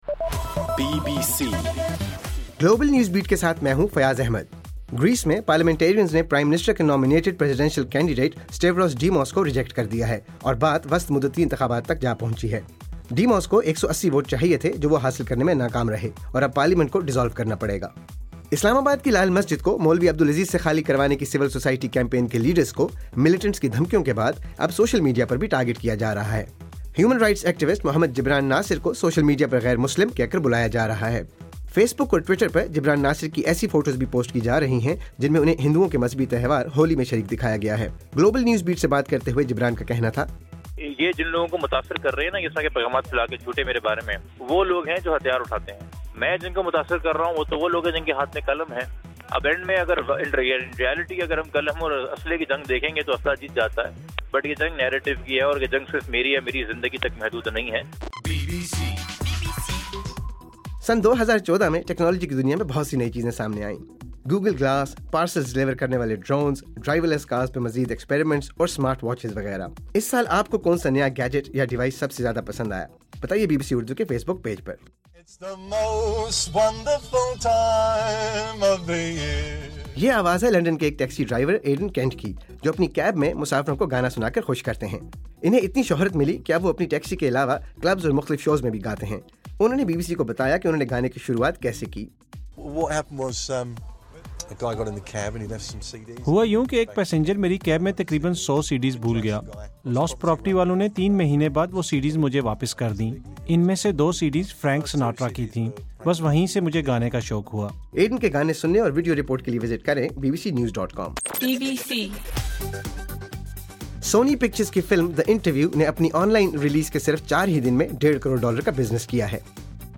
دسمبر 30: صبح 1 بجے کا گلوبل نیوز بیٹ بُلیٹن